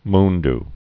(mnd)